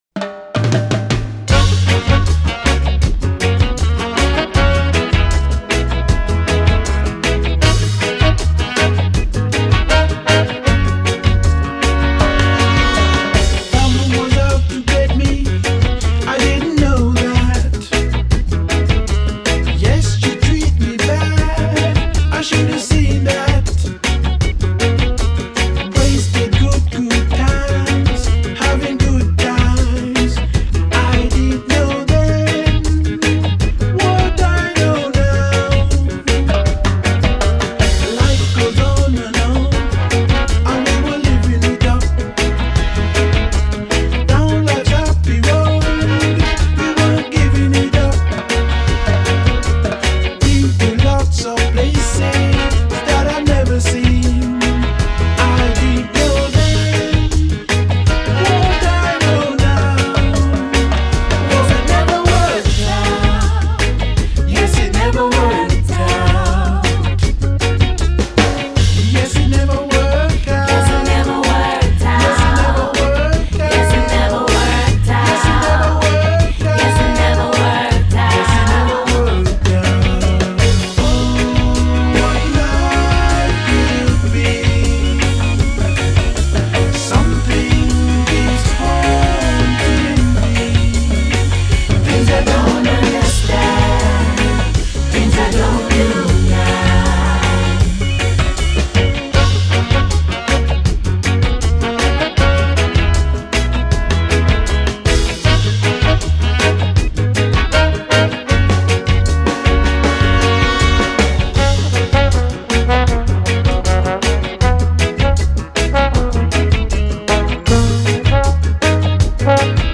Genre: Ska, Reggae, Dub